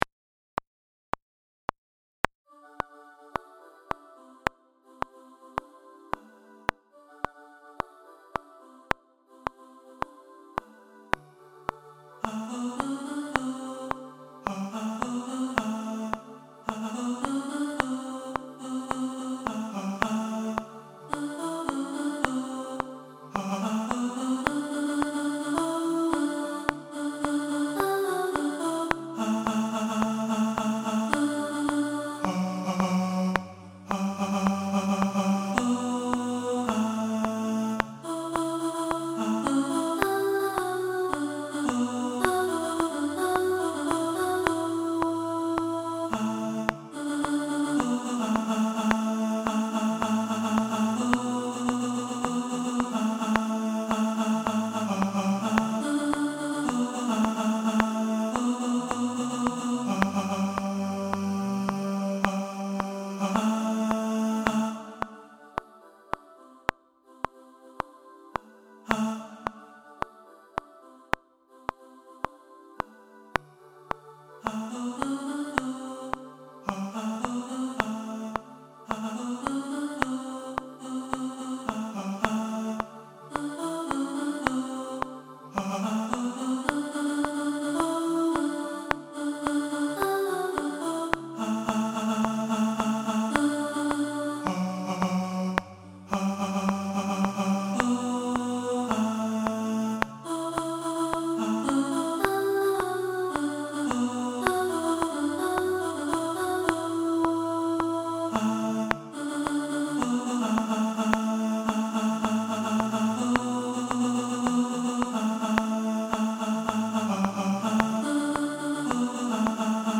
Rhythm-Of-The-Night-Tenor.mp3